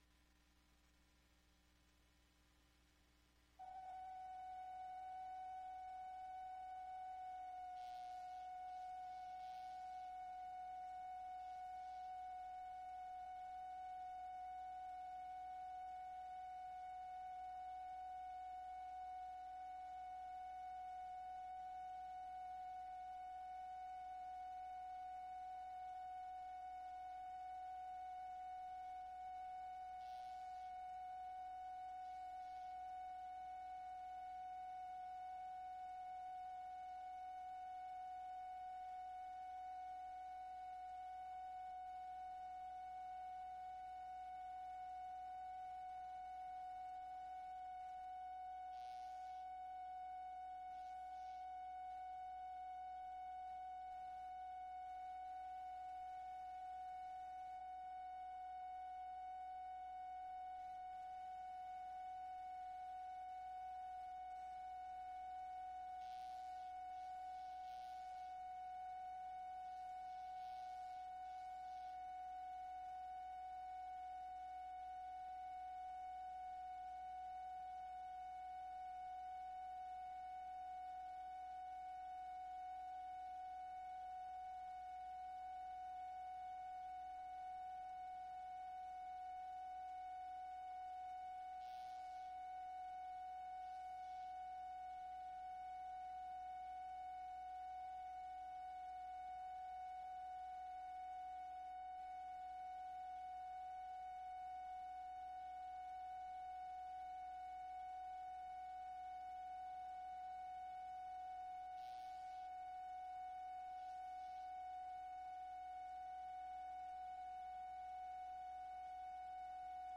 President’s Radio Broadcast on his recovery from surgery, Bethesda Hospital